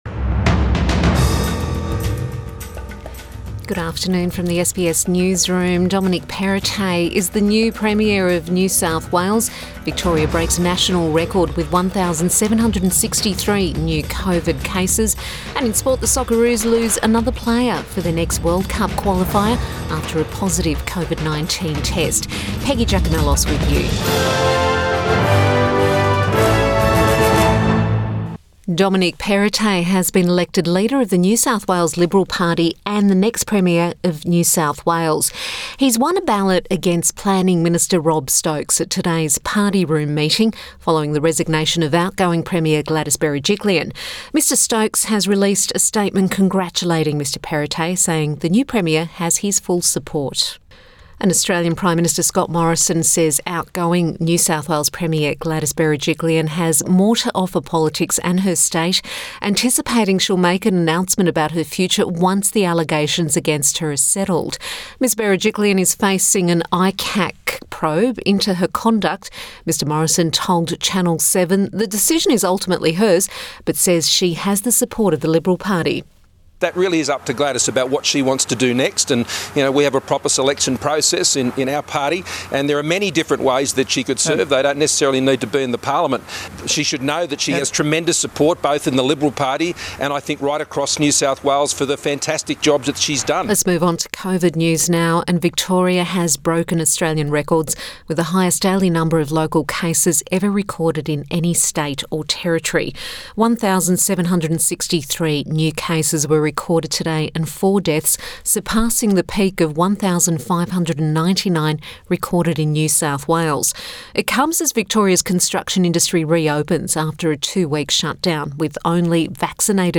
Midday bulletin October 5 2021